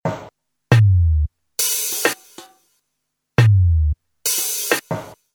reggae drumloops soundbank 2